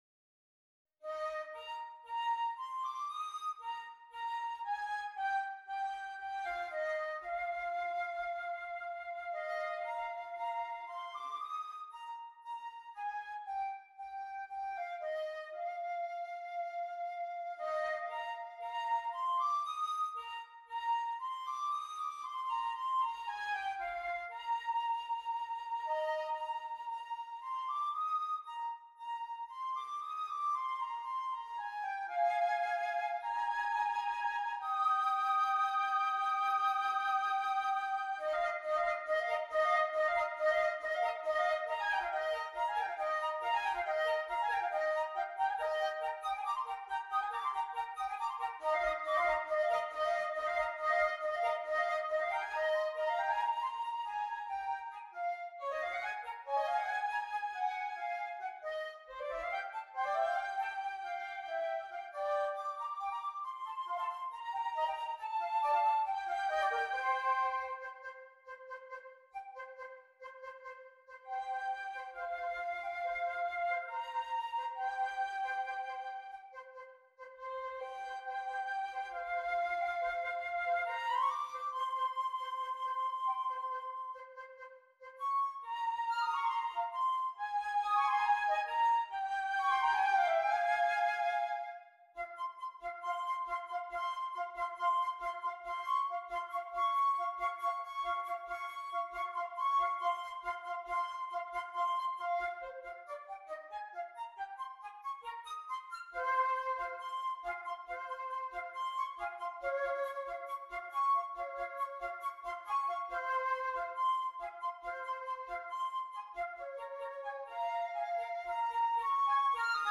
2 Flutes